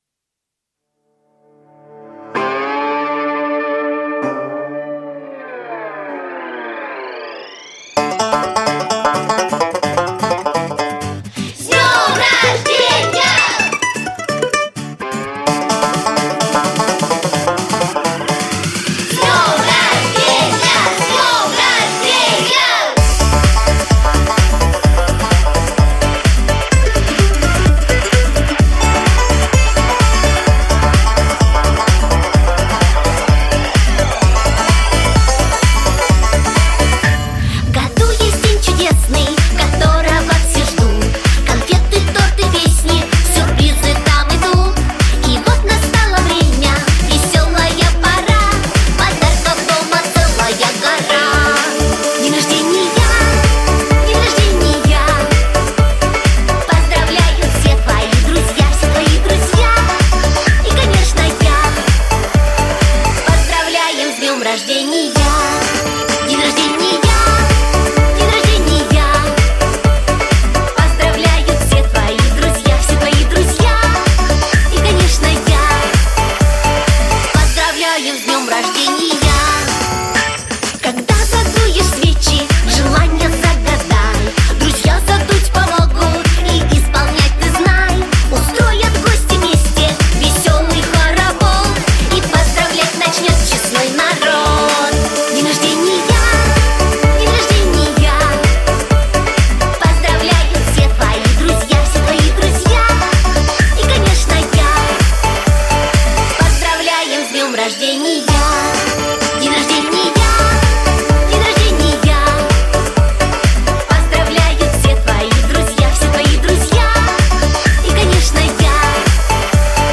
• Категория: Детские песни
малышковые